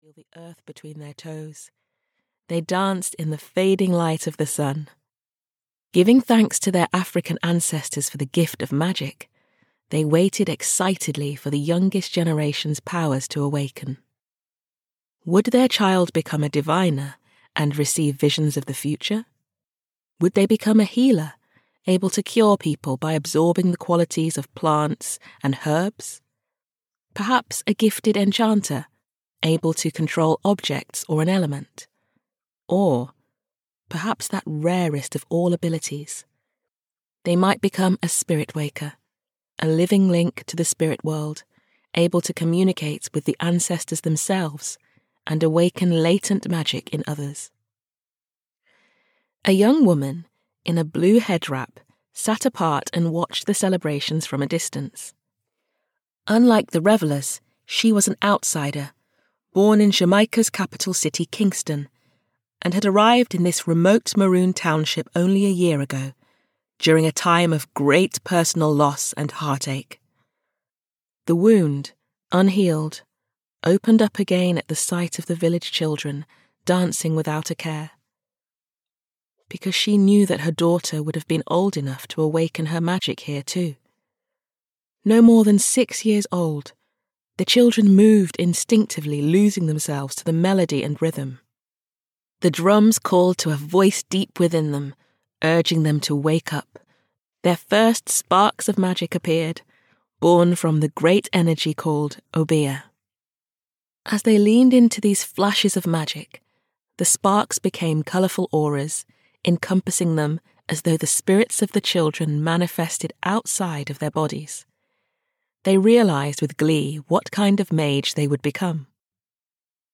Spirit Warriors (EN) audiokniha
Ukázka z knihy